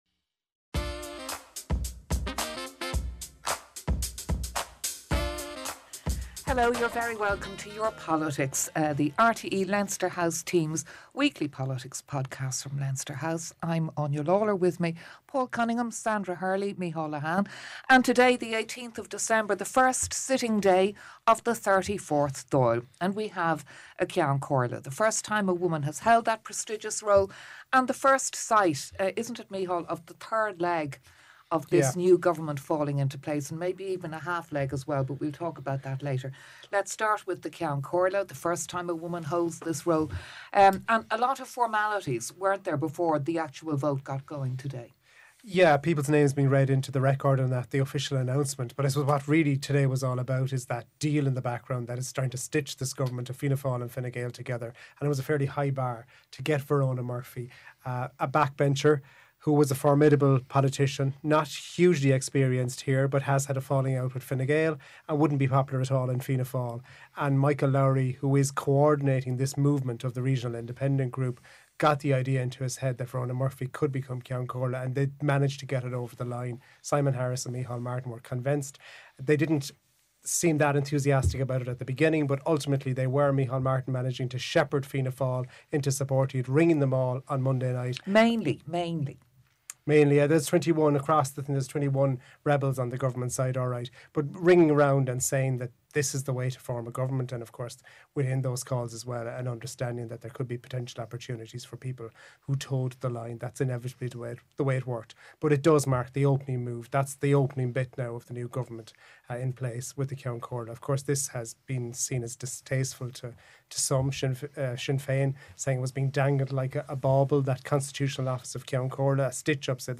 The RTÉ team at Leinster House and guests have an informal discussion about what has been happening in the world of Irish politics.